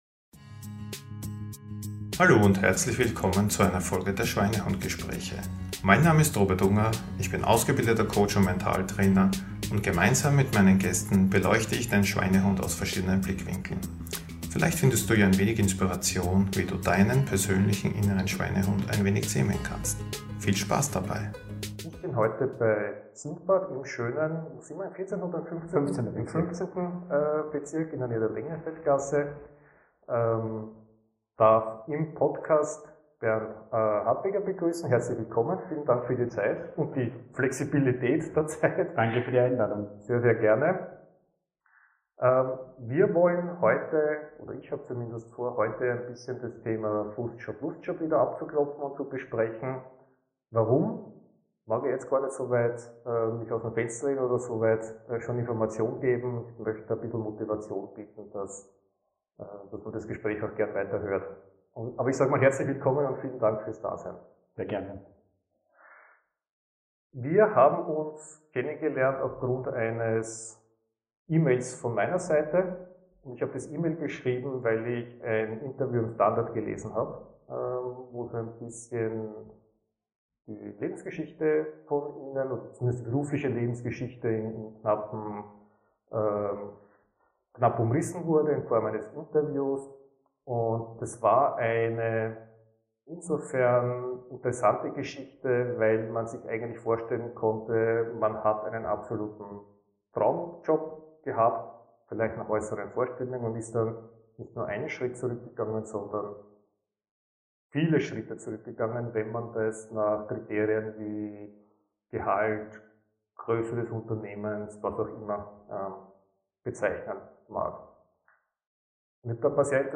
Ein Gespräch über Werte, Entscheidungen und die Kraft, neue Wege einzuschlagen.